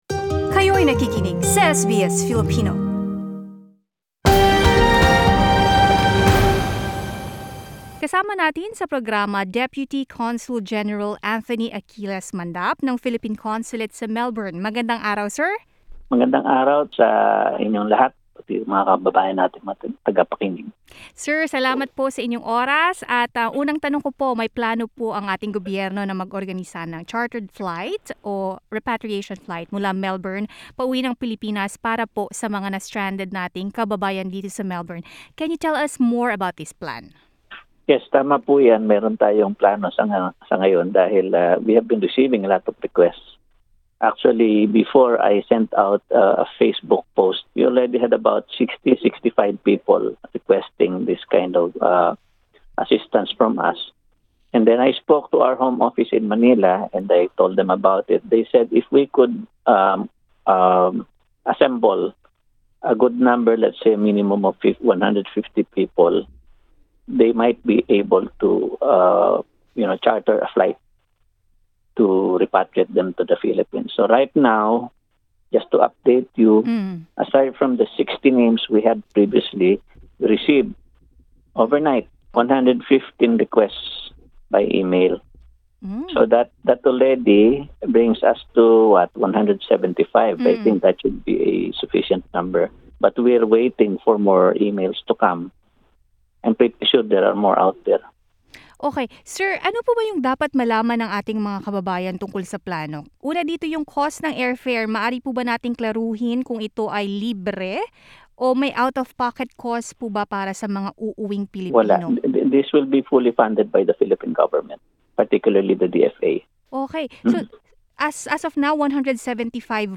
Deputy Consul General Anthony Achilles Mandap shares the details of their plan in an interview with SBS Filipino and says his office received many requests from stranded Filipinos in Victoria asking for their assistance.